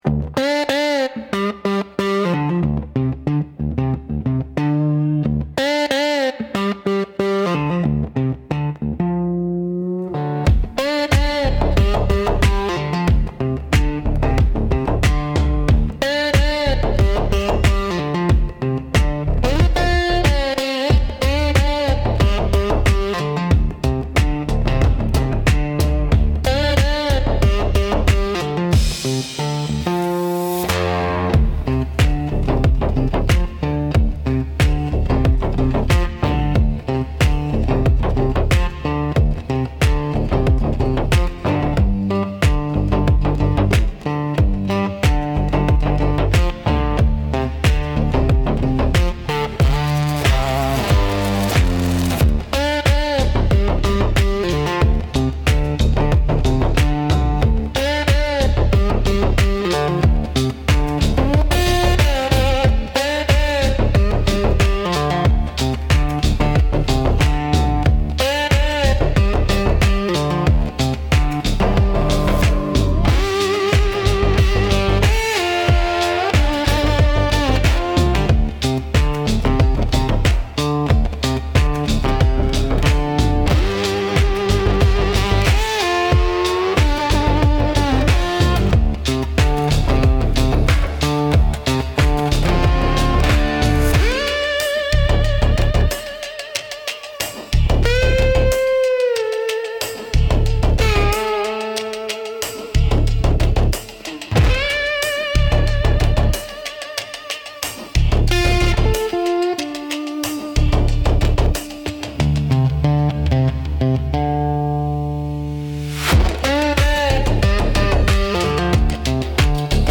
Instrumental - Danger Dressed in Red